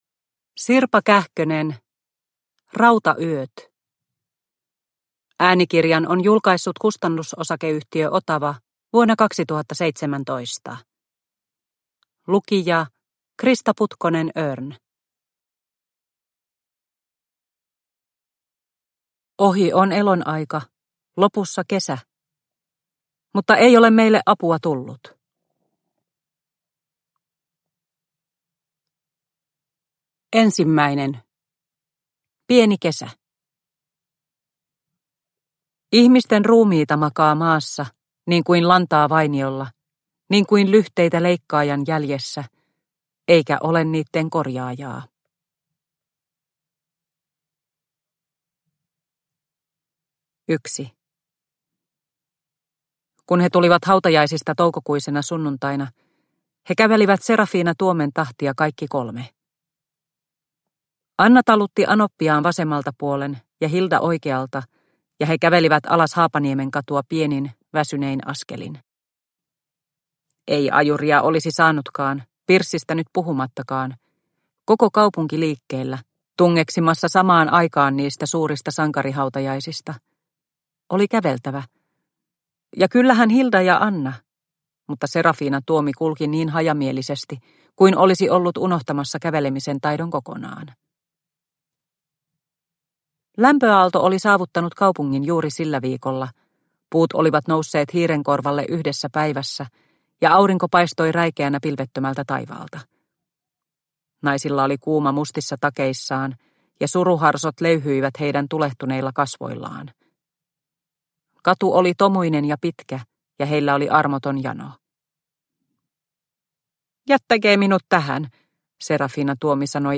Rautayöt – Ljudbok – Laddas ner